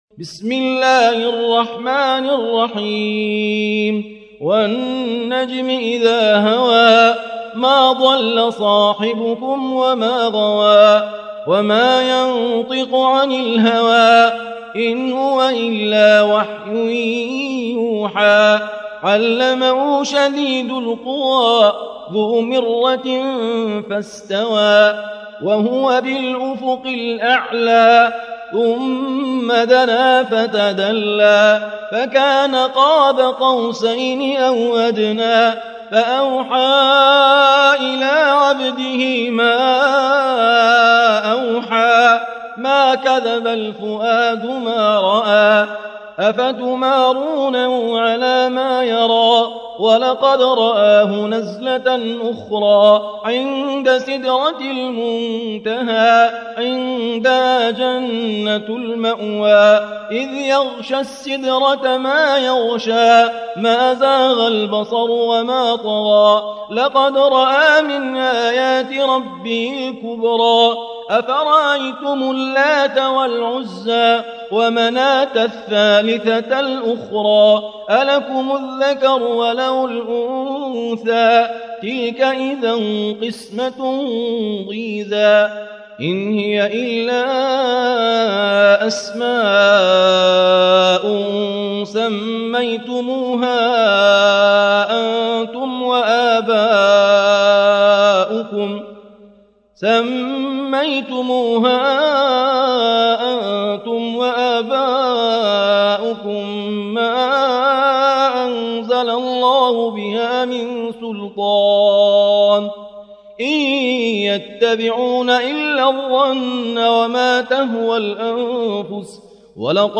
التصنيف: تلاوات مرتلة